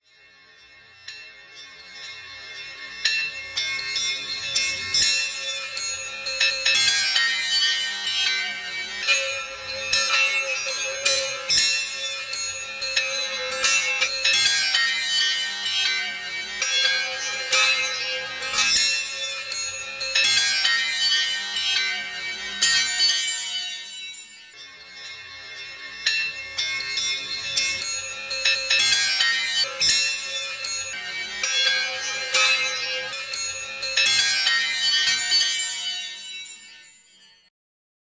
唸るような声や何か迫ってくる恐怖を感じる効果音の金属音風のホラー音。